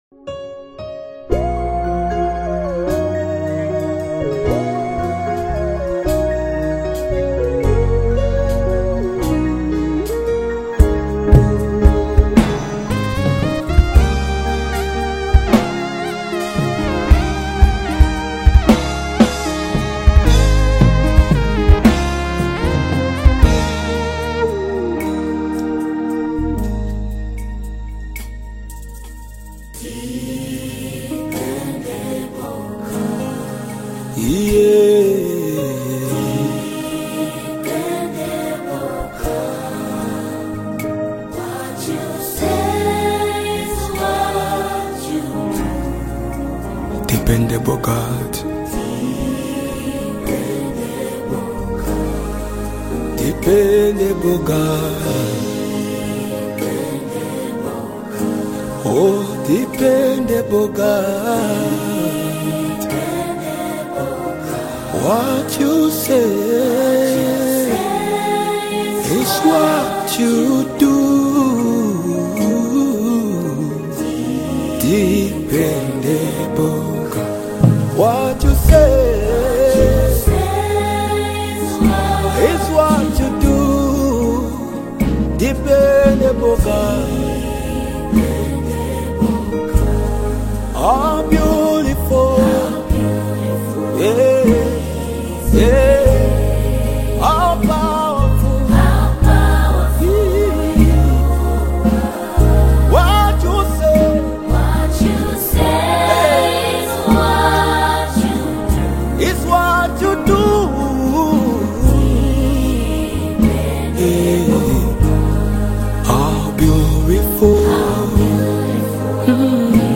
With deeply inspiring lyrics and passionate vocals